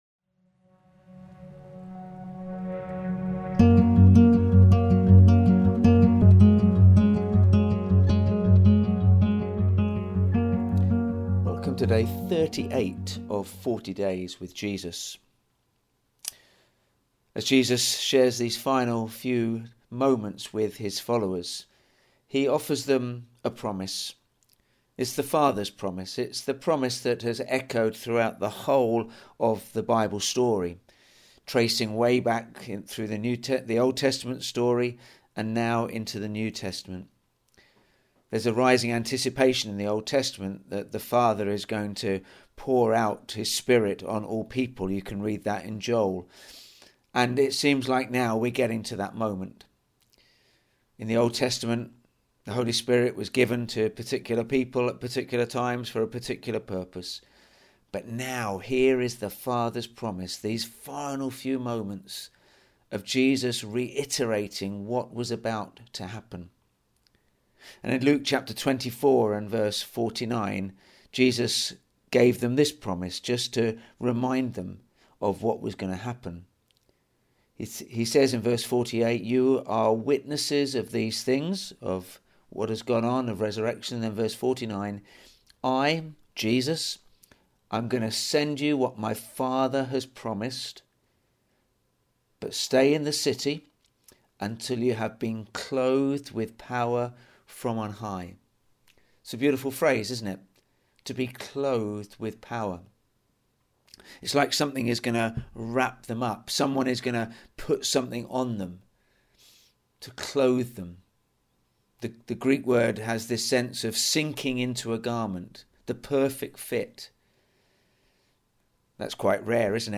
We're in the final week of this preaching series and these daily podcasts fill in some of the gaps from Sunday to Sunday. We will be posting short, daily reflections as we journey through the encounters people had with the risen Jesus.